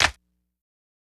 CLAP_INSANITY.wav